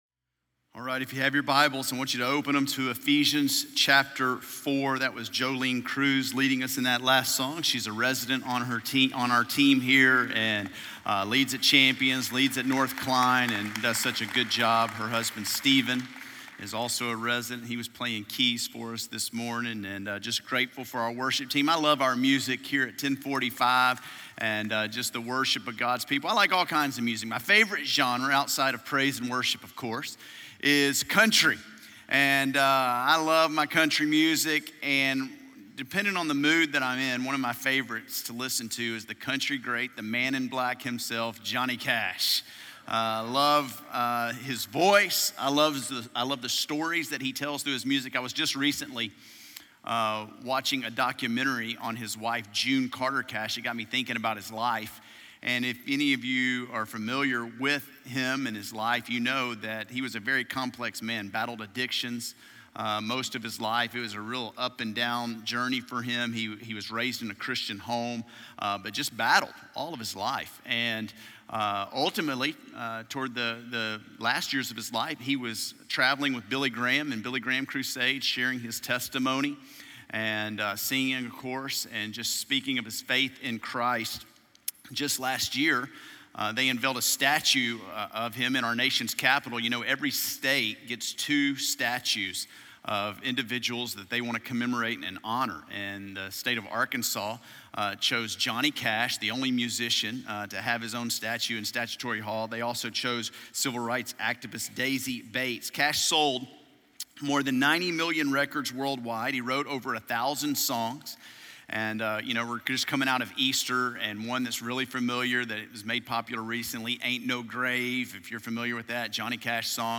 Champion Forest Baptist Church is a welcoming, multi-site church in Northwest Houston serving the Champions, Spring, and Klein communities.